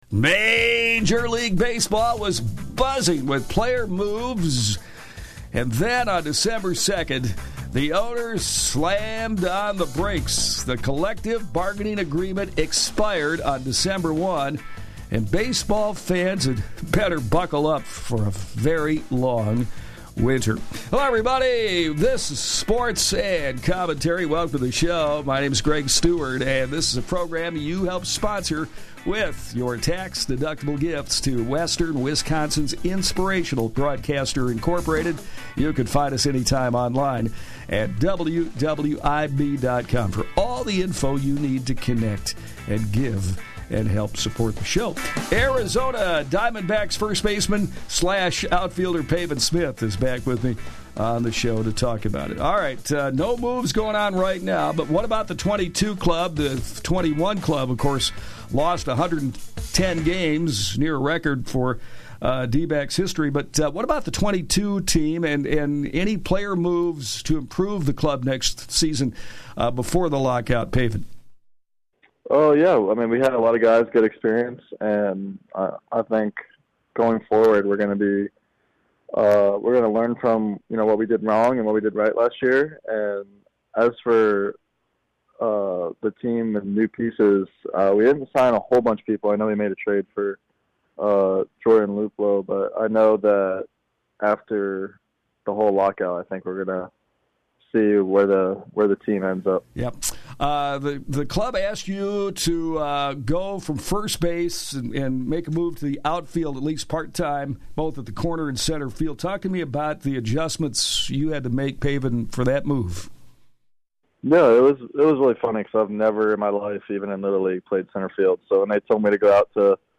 Share to X Share to Facebook Share to Pinterest Labels: High School Sports